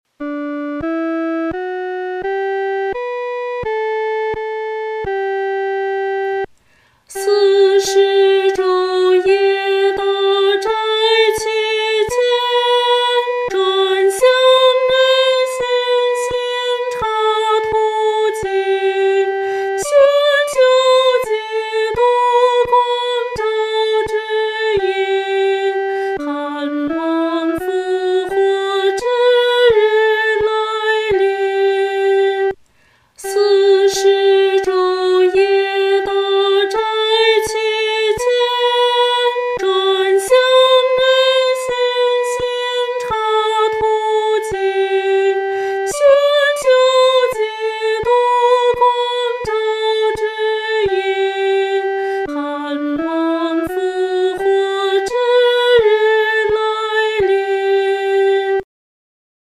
女高
这首诗歌充满着虔敬和恳切，我们在弹唱时的速度不宜太快。